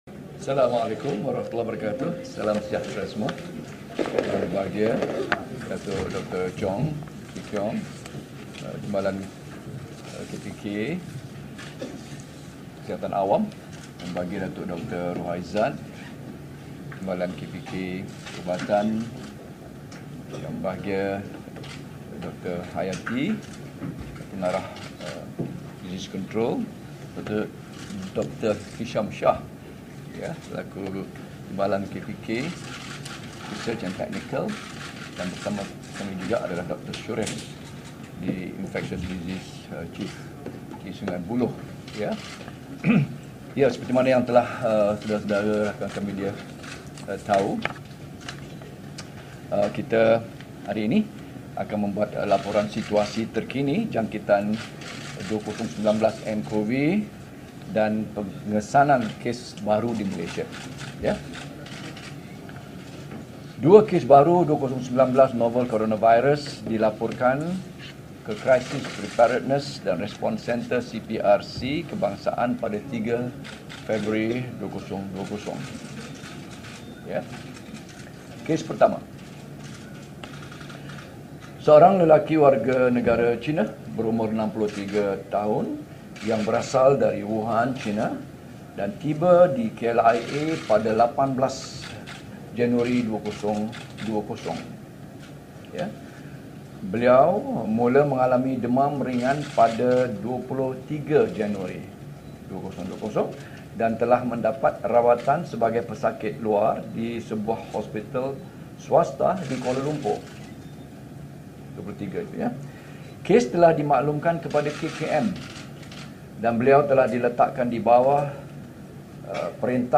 Ikuti sidang media Menteri Kesihatan, Datuk Seri Dr Dzulkefly Ahmad berhubung penularan wabak koronavirus.